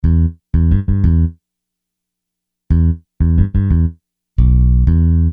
Hiphop music bass loops 4
Hiphop music bass loop - 90bpm 68